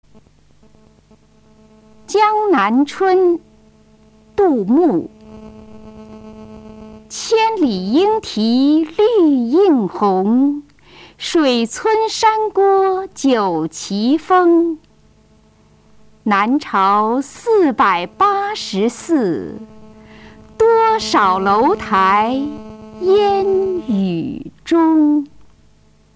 杜牧《江南春》原文和译文（含赏析、朗读）